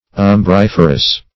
Search Result for " umbriferous" : The Collaborative International Dictionary of English v.0.48: Umbriferous \Um*brif"er*ous\, a. [L. umbrifer; umbra a shade + ferre to bear.] Casting or making a shade; umbrageous.